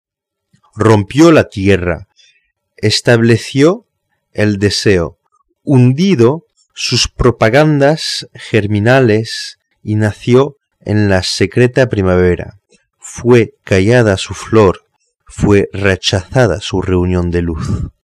POUR ENTENDRE LA PRONONCIATION EN ESPAGNOL